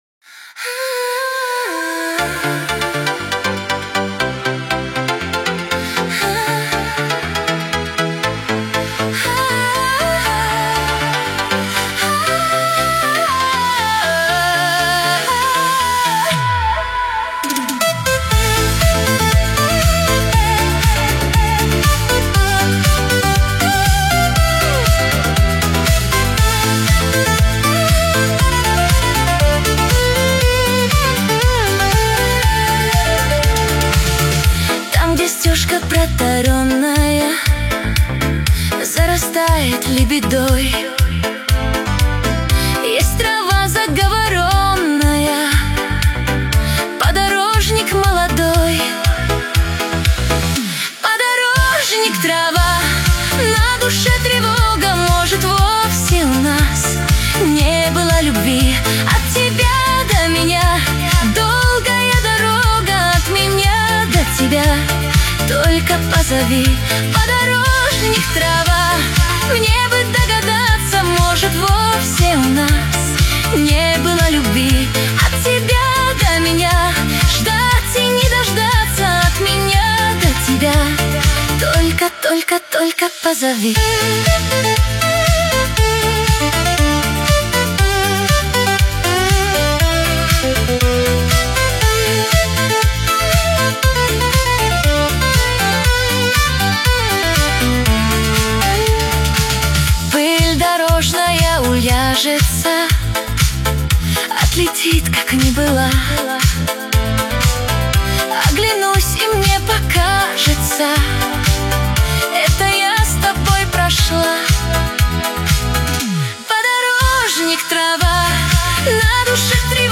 Танцевальный Шансон